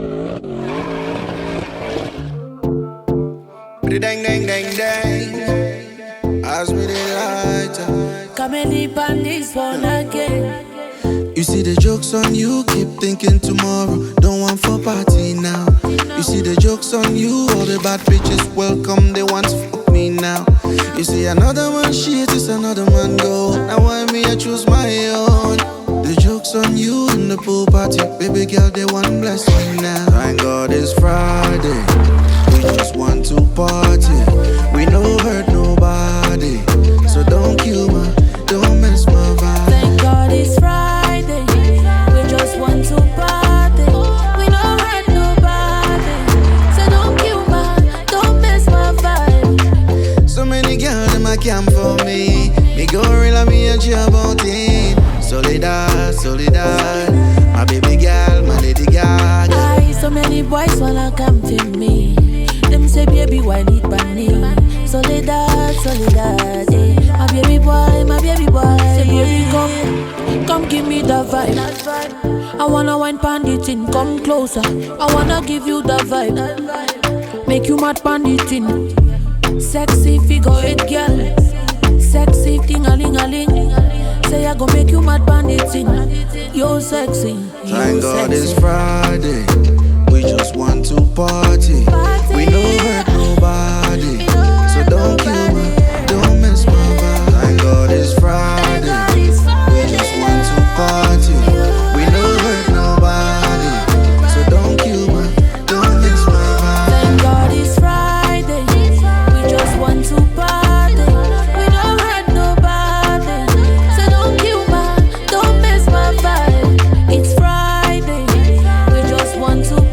With its upbeat tempo and catchy sounds